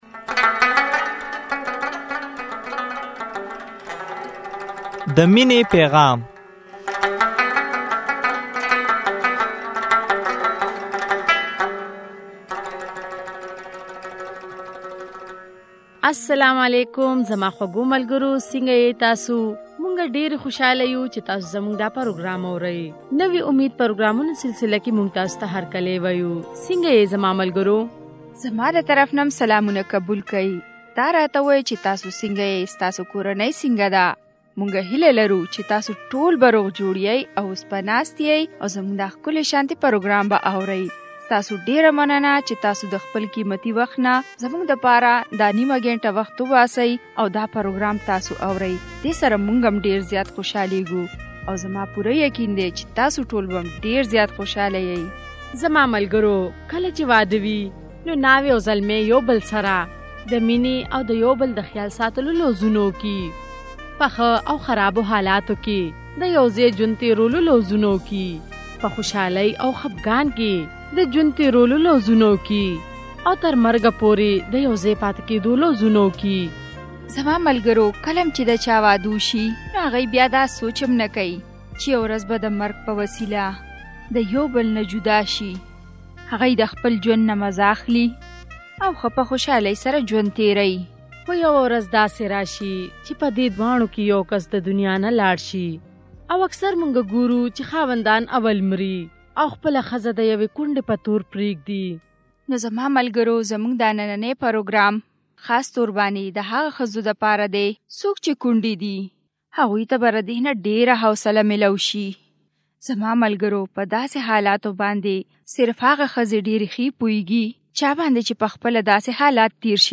دوه ښځې د خپلو خاوندانو د مرګ غم، صدمې او د افسوس اظهار کوى. د جماعت خلقو، ملګرو او کورنۍ د هغوئ په روحانى او عملى توګه مرسته وکړه.